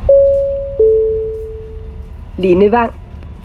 Højttalerudkald Metro og Letbane